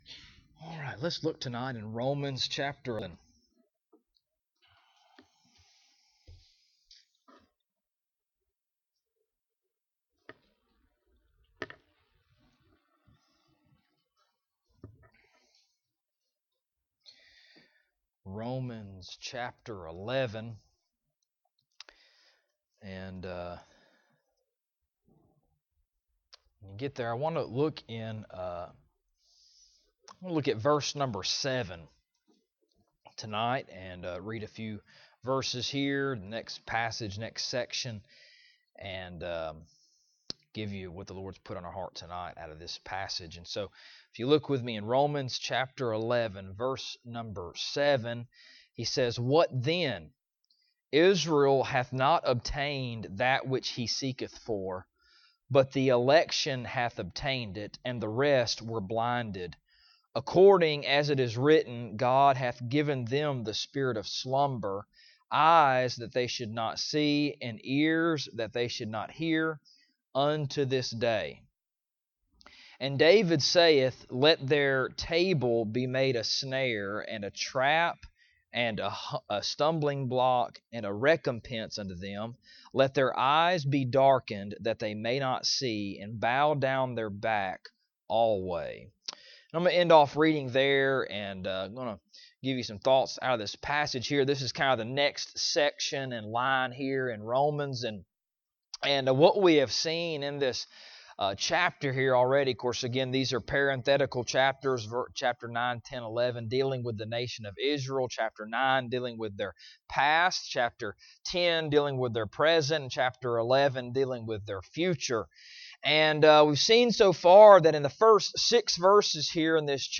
Romans Passage: Romans 11:7-10 Service Type: Wednesday Evening « Hath God Cast Away His People?